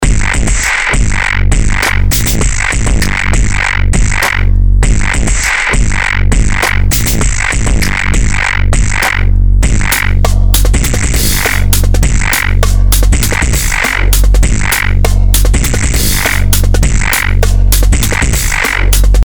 Auf Basis der braven TB 808 können dann auch solche abgedrehten Geräuschsounds entstehen: